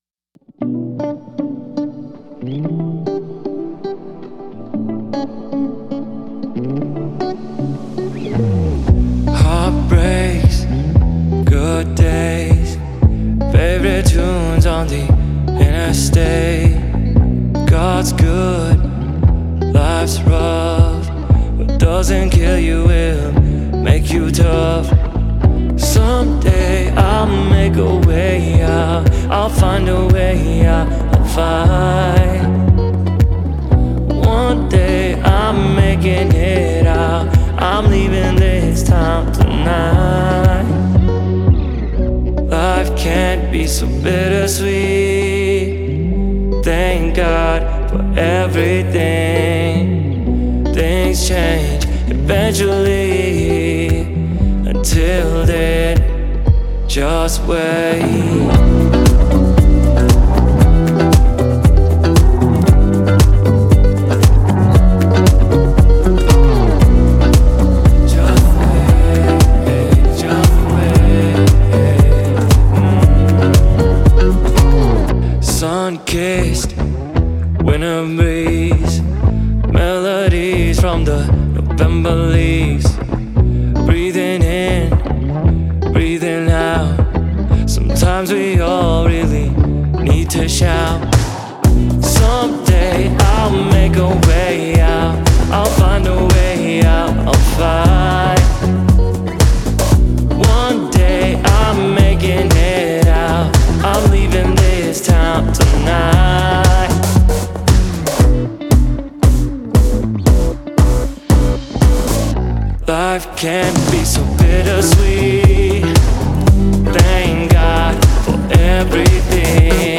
BPM: 116